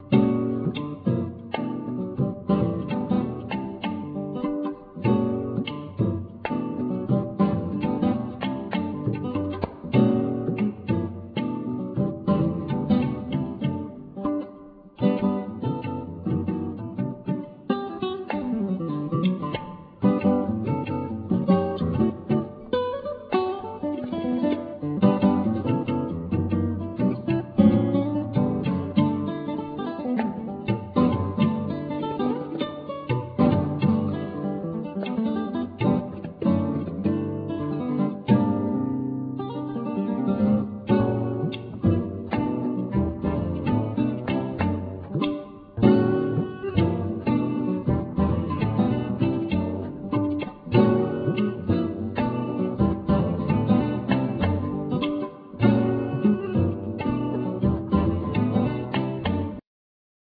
Oboe, Bass clarinet, Soprano & Sopranino Sax, Whistles
Guitars, Pianos, Synthesizers
Bass, Piano
Percussions, Voice